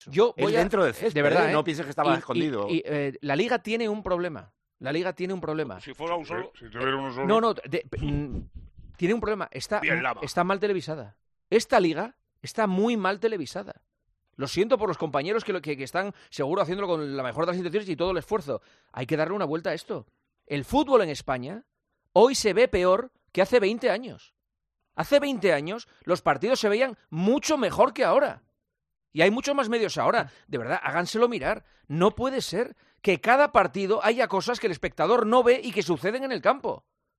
Juanma Castaño explica lo que no le gustó del homenaje a Vinicius y provoca un debate en El Partidazo de COPE